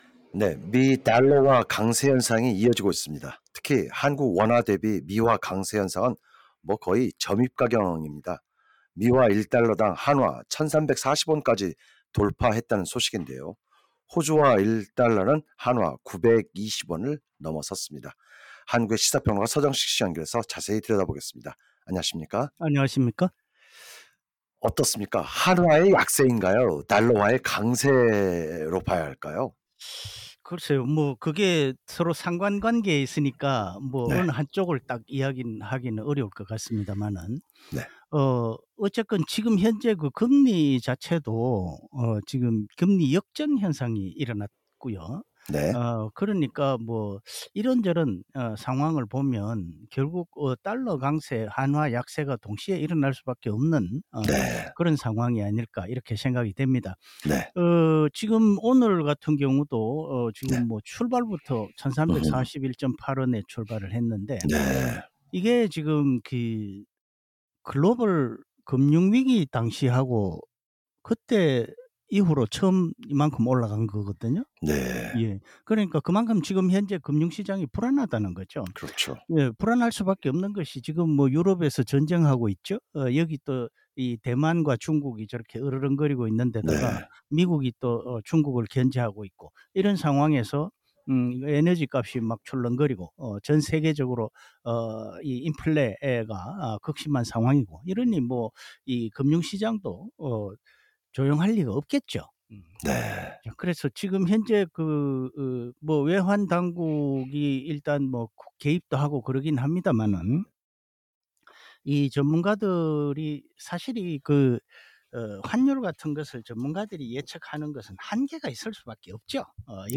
해설: 시사 평론가